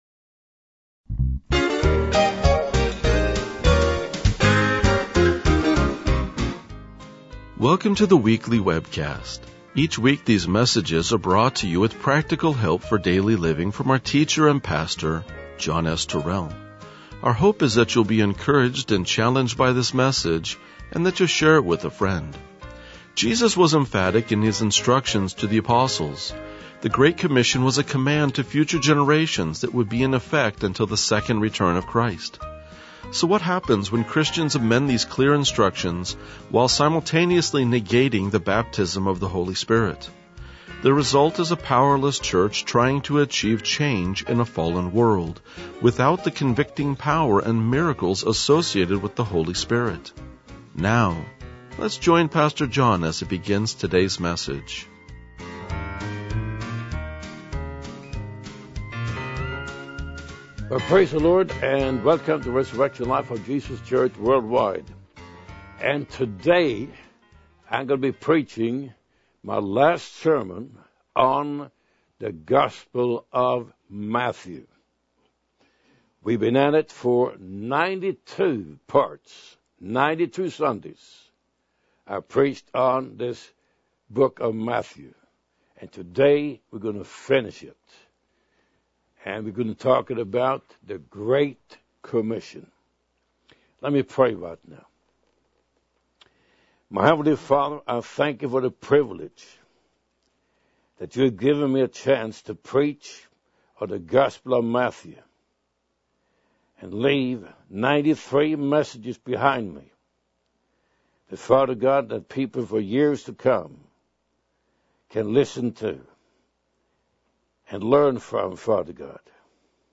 RLJ-1996-Sermon.mp3